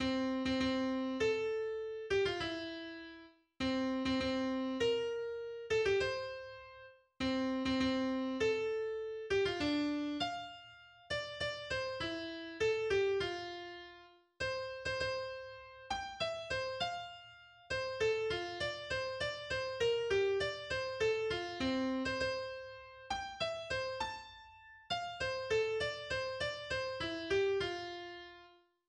baierisches Volkslied